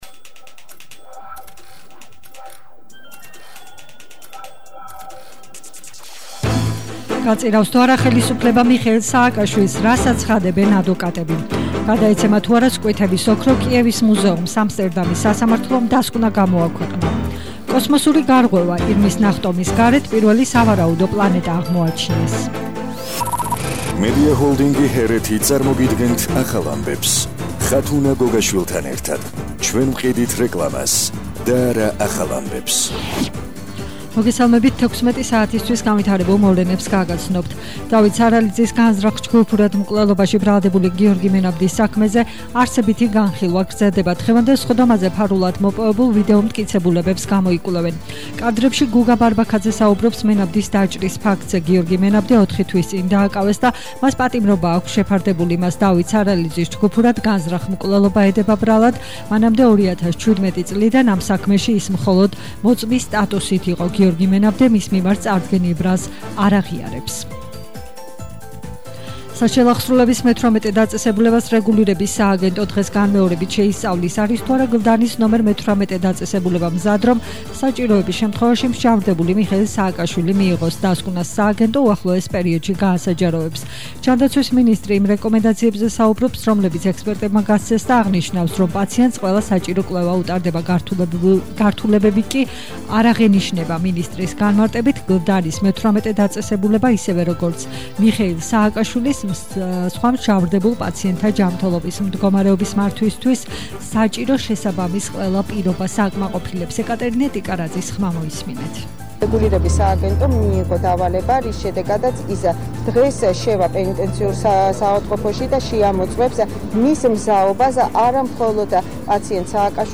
ახალი ამბები 16:00 საათზე –26/10/21 - HeretiFM